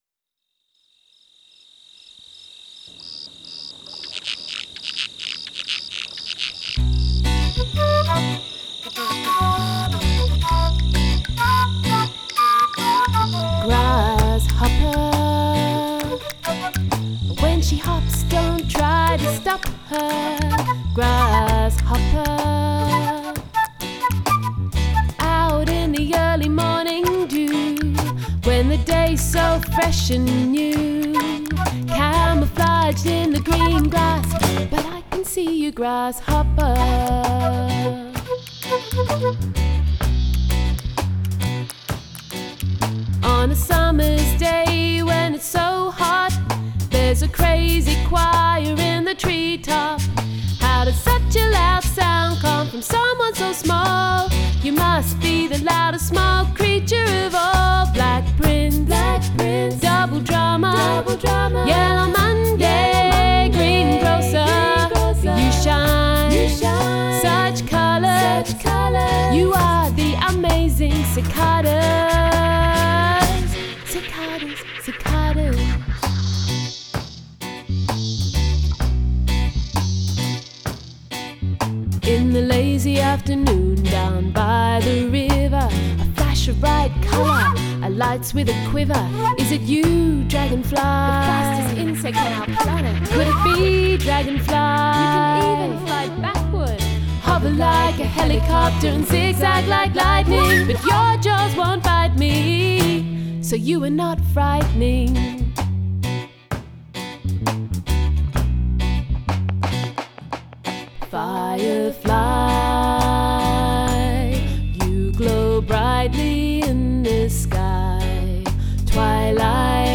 parent friendly children’s album